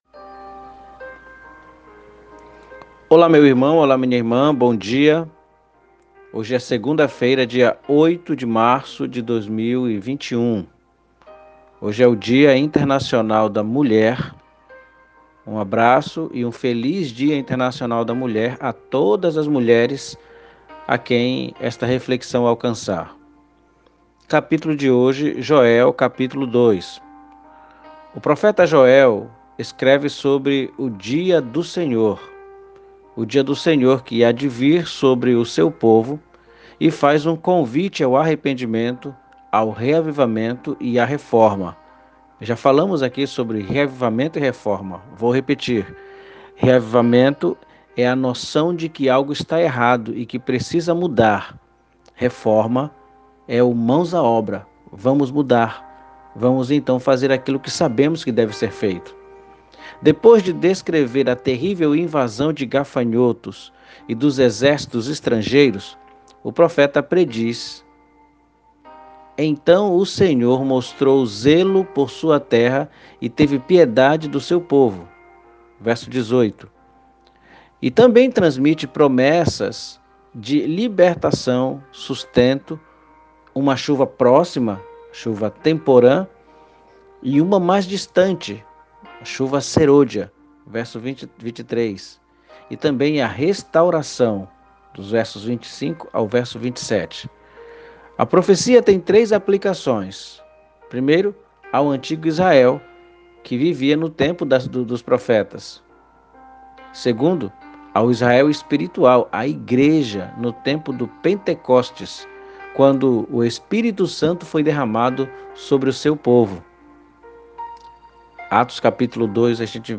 MEDITAÇÃO BÍBLICA